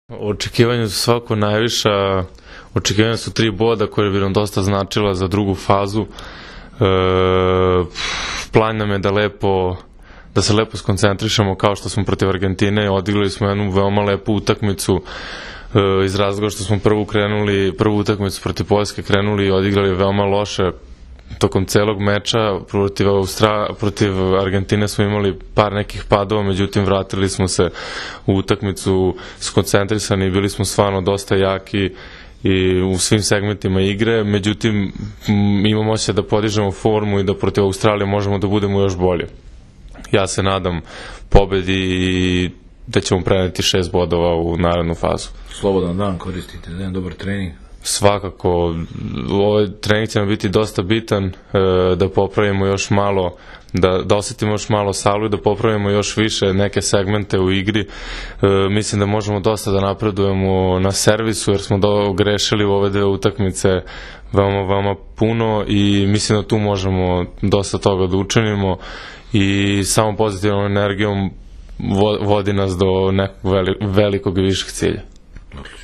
IZJAVA NIKOLE JOVOVIĆA